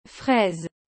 Qual é a pronúncia correta de fraise?
A palavra fraise se pronuncia como “frèz”, com o “é” aberto e o “s” final soando como “z”.